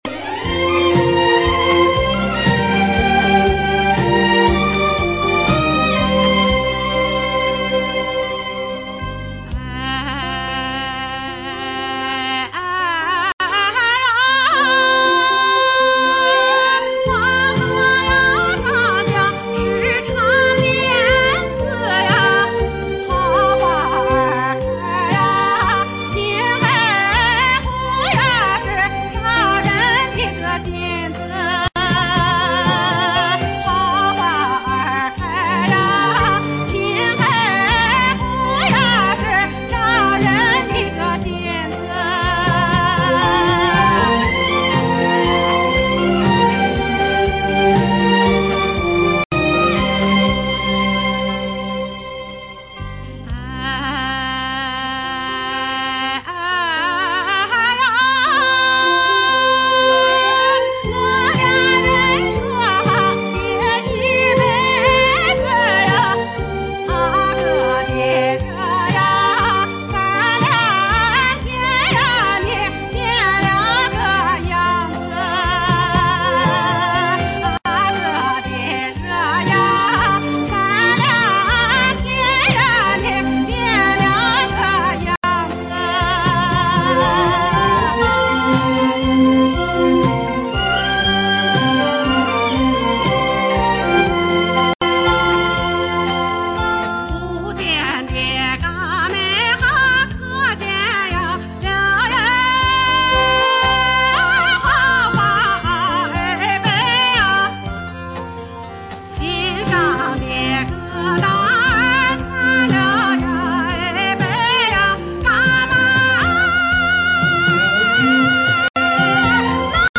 青海花儿